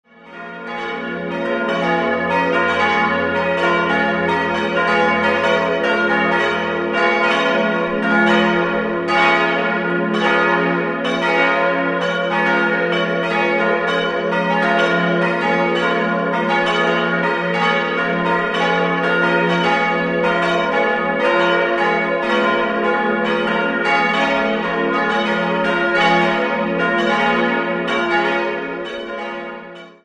Idealquartett: f'-as'-b'-des''
Marienglocke
bell
Ein erstaunlich klangvolles und sehr hörenswertes Geläute der Gießerei Hahn. Glockenstuhl- und -joche sind aus Stahl.